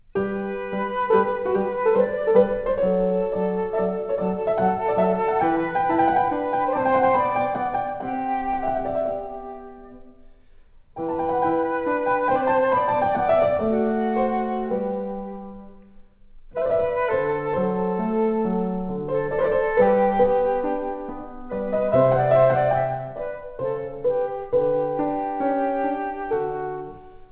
piano Bösendorfer
flute)hu